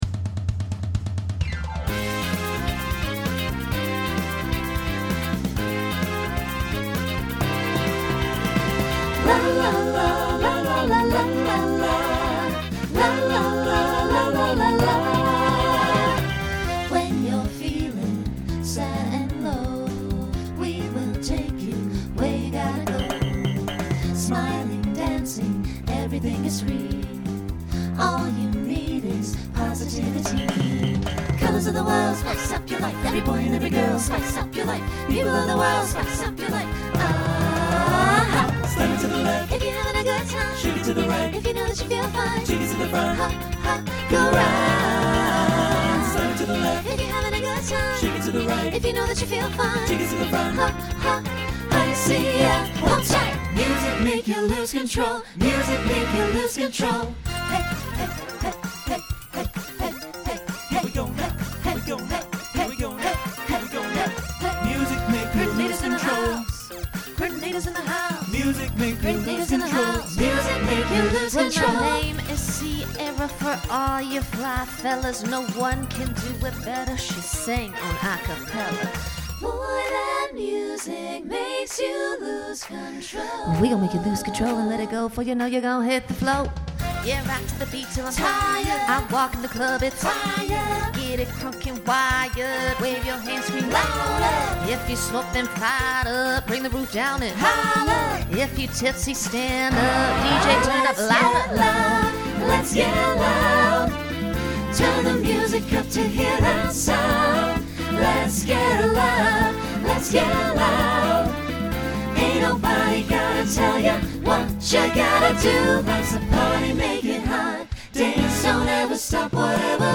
Genre Latin , Pop/Dance
Voicing SATB